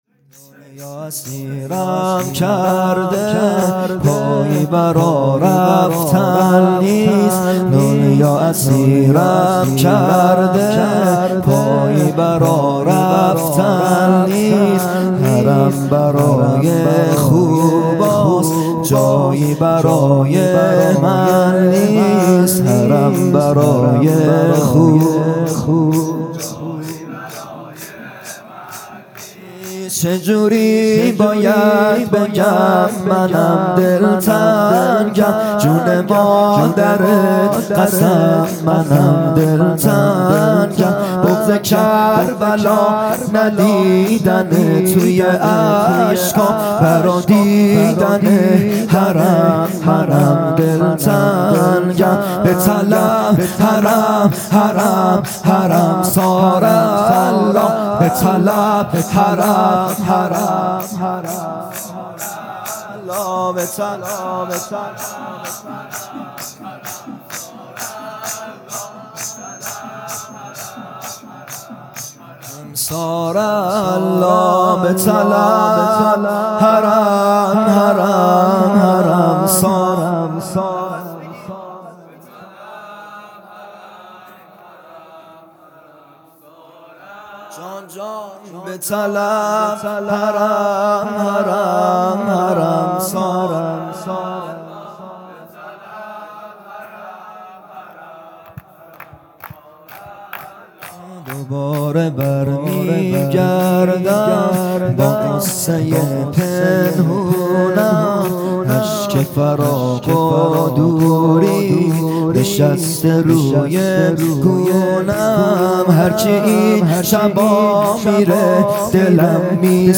شور | دنیا اسیرم کرده، پایی برا رفتن نیست
جلسۀ هفتگی | به مناسبت شهادت حضرت معصومه(س) | ‍به یاد شهید آیت الله حسن مدرس | 14 آذر 1398